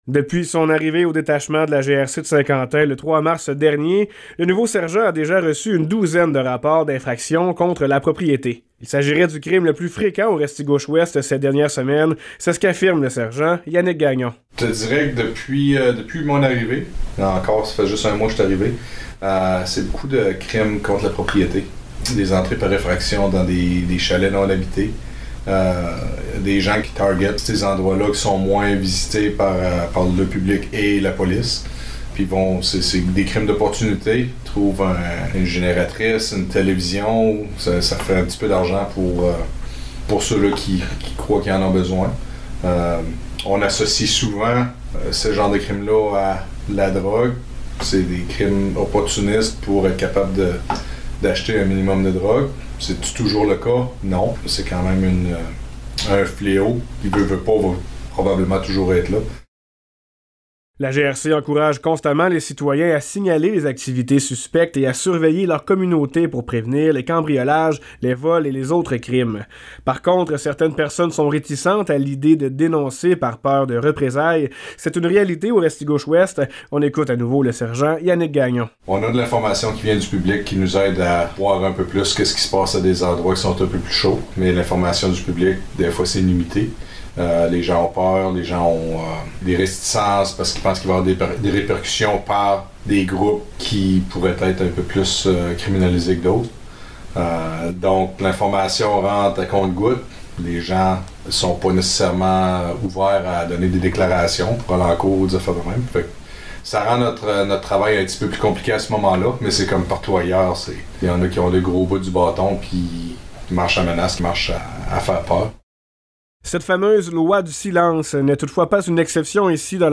Reportage
reportage-effractions.mp3